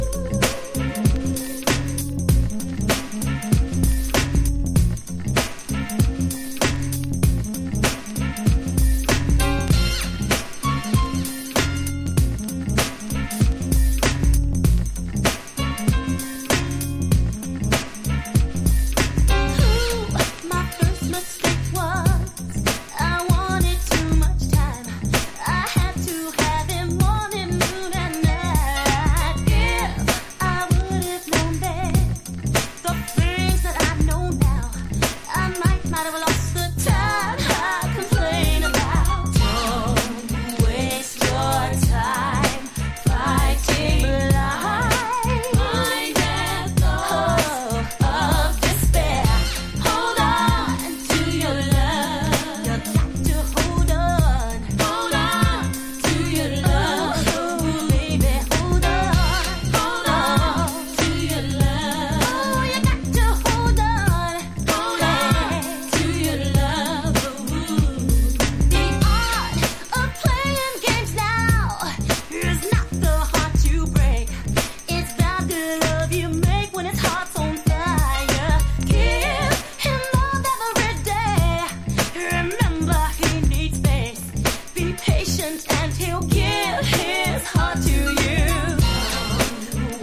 実力を持った4人組ガールズ・グループ